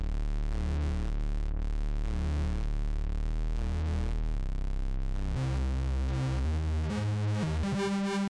i dont feel like going through all my presets now but here’s six i just did. some are more similar just gritty fm brassy stuff, but i there is a nice quality to the synth where you can get high notes that are clean woody almost digital dx fm sounding but organic while the low notes and chords are fuzzy and warm at the same time. I’ll try to find some of those later, but these are just some i was able to record quickly just now. sorry about the volume differences, i forgot to normalize a couple. also excuse my playing, i suck